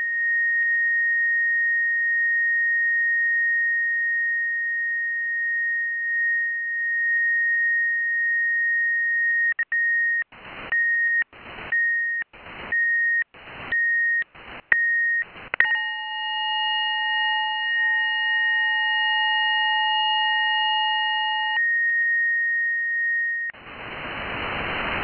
Начало » Записи » Радиоcигналы на опознание и анализ
Неизвестный сигнал